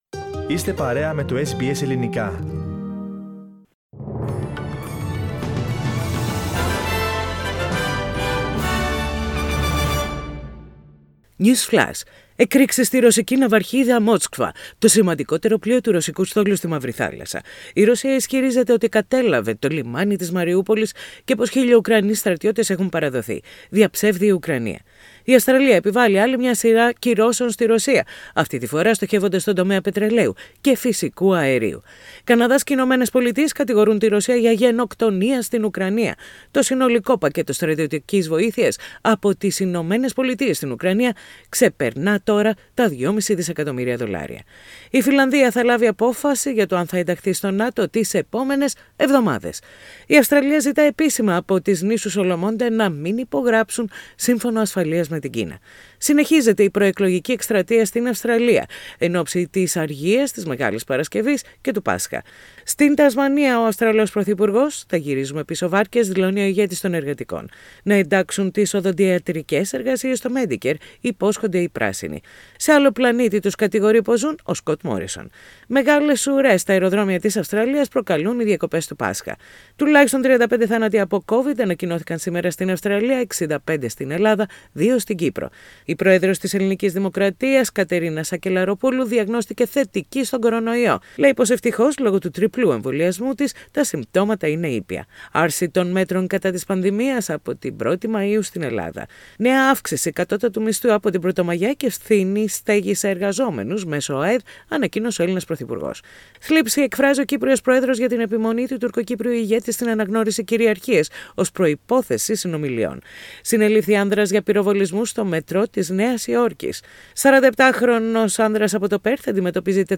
News Flash - Σύντομο Δελτίο Ειδήσεων - Πέμπτη 14.4.22